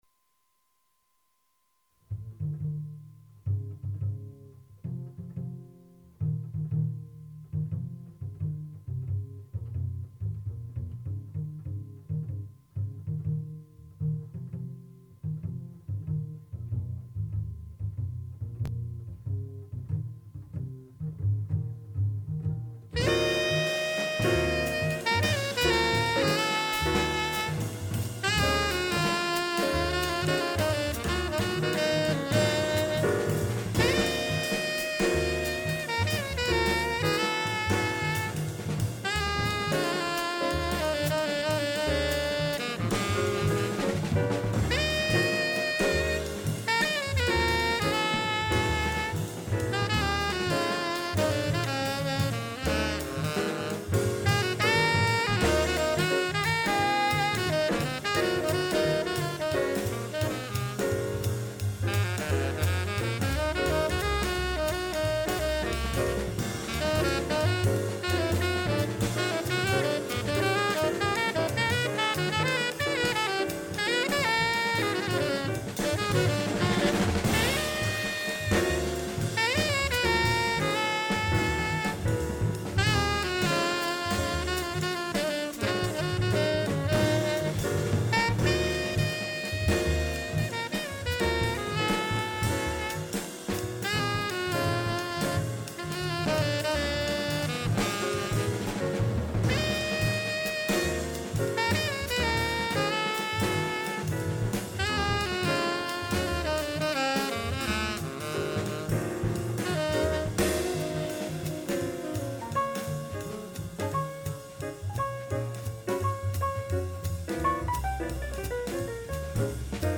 jazz drummers